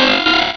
Cri de Goélise dans Pokémon Rubis et Saphir.